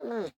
Minecraft Version Minecraft Version 25w18a Latest Release | Latest Snapshot 25w18a / assets / minecraft / sounds / mob / armadillo / ambient3.ogg Compare With Compare With Latest Release | Latest Snapshot